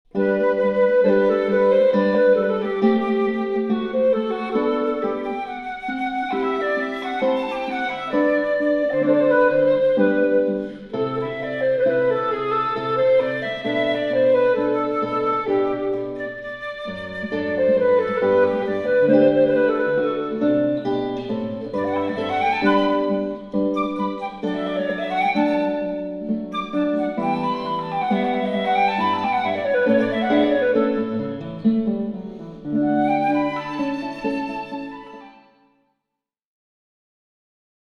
flute/guitar
a 5-channel upmix with reverb as heard over the five
I imagine sitting in my 5-ch listening room listening to 5-speaker replay of a 5-ch recording of the duo playing in a concert hall.